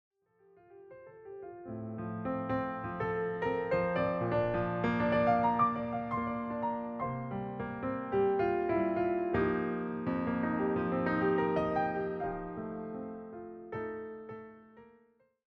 all performed as solo piano arrangements.